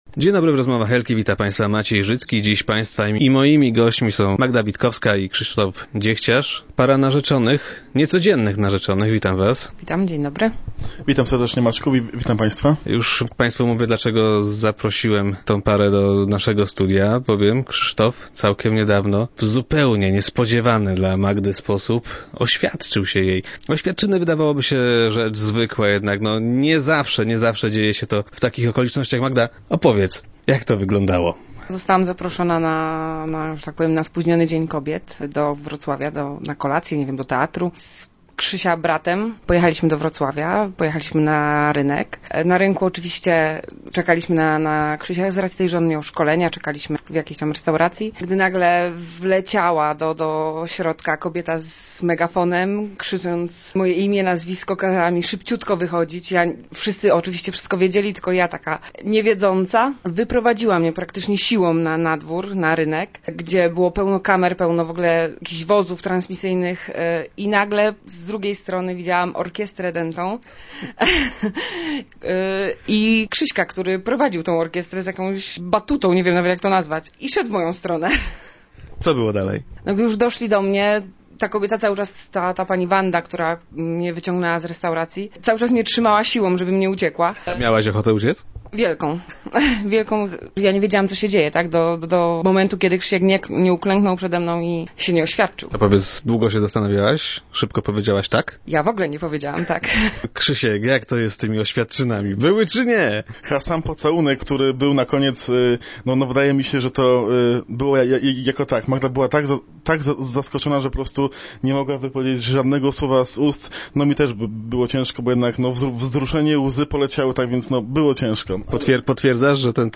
Dziś narzeczeni byli gośćmi Rozmów Elki.